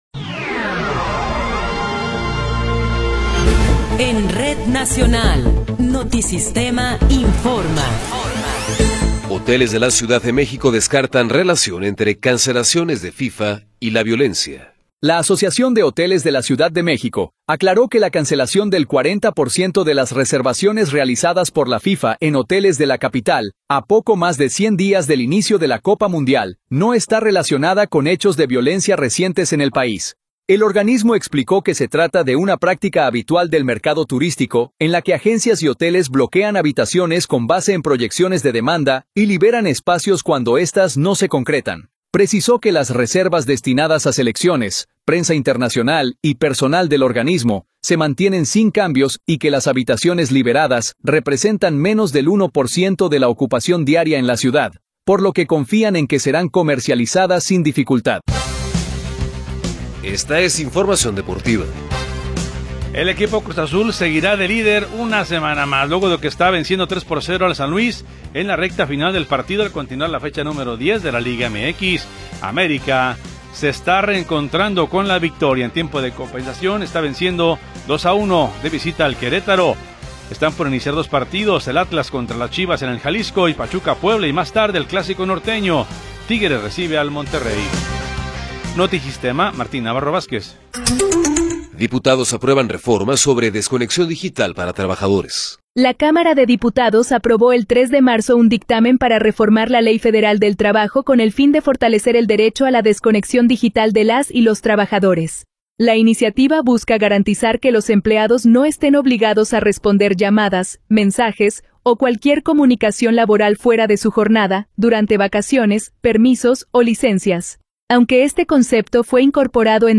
Noticiero 19 hrs. – 7 de Marzo de 2026